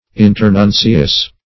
Internuncius \In`ter*nun"ci*us\, n.